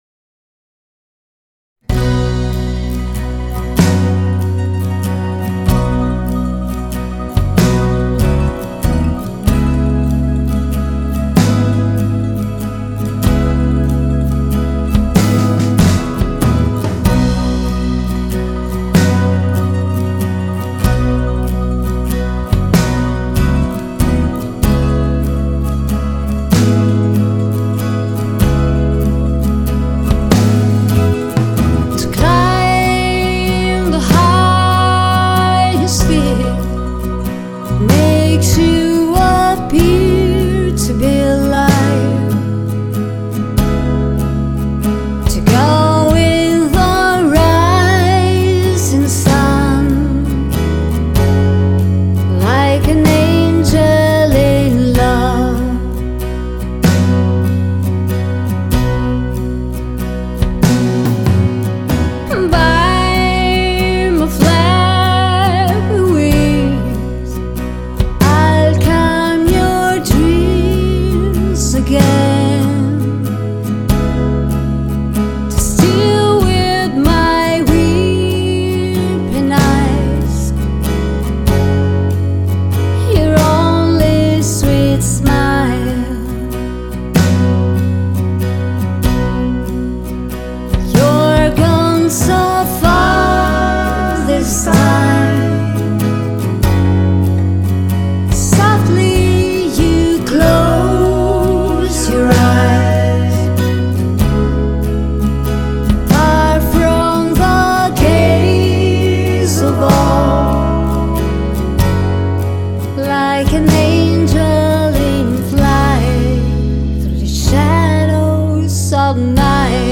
• Genre: Country / Pop
Lead Vocal
Background Vocals, Acustic Guitars, Lead Electric Guitar
Bass, Drums, keyboards